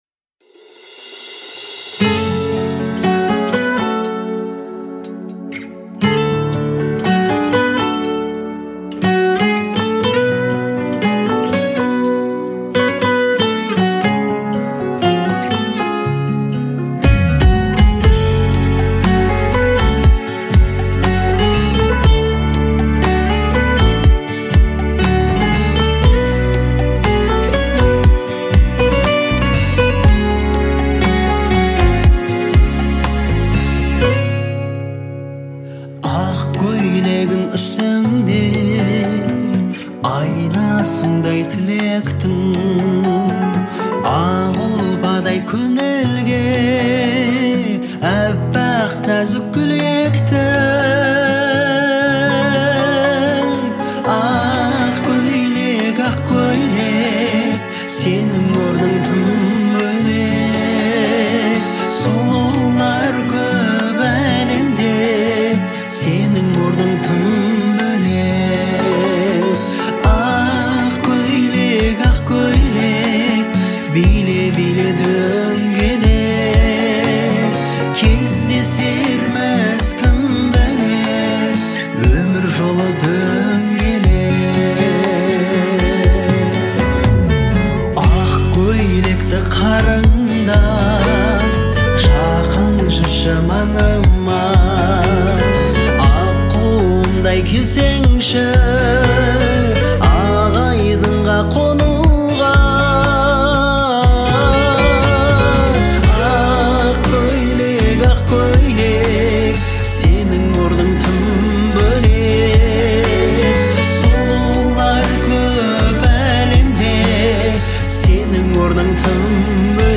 относится к жанру казахской поп-музыки.